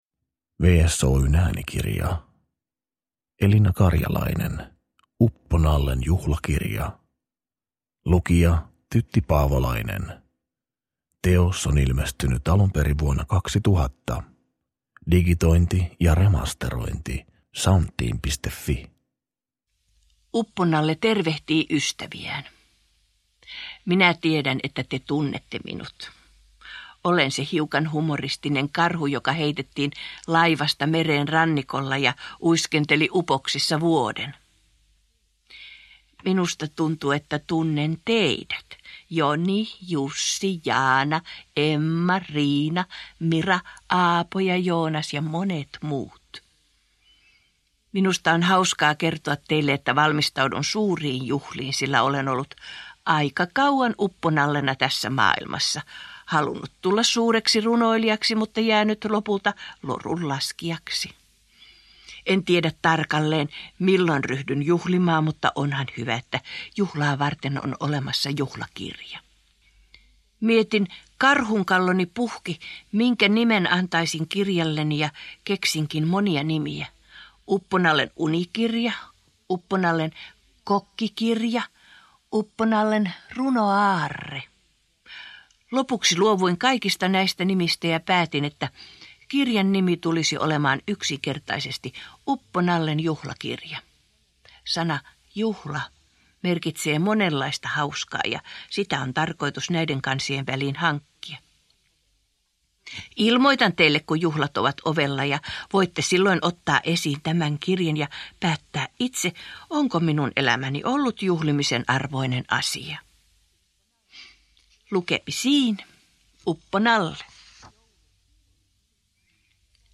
Uppo-Nallen juhlakirja – Ljudbok – Laddas ner